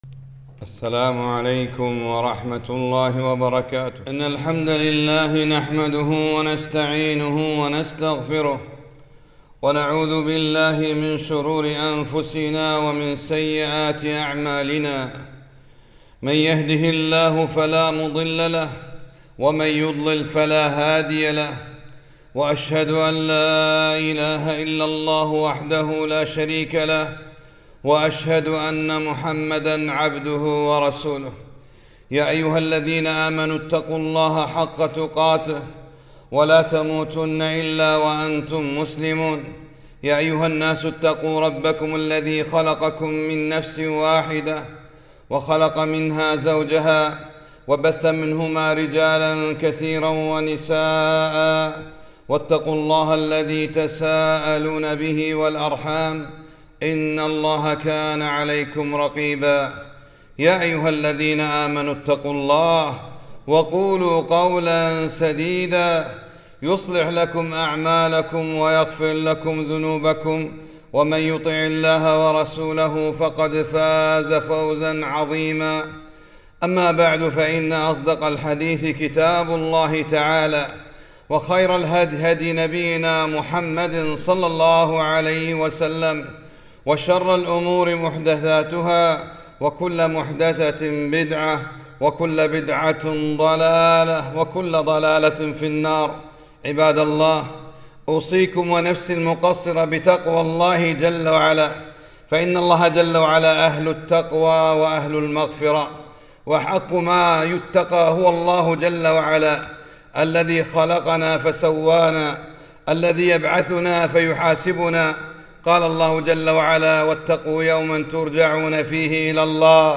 خطبة -